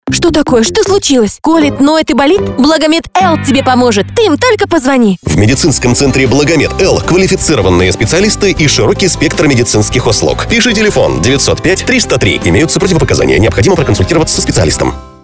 Стартовало размещение рекламы на радиостанции "Русское радио" медицинского центра "БлагоМед-Л" в г. Липецке.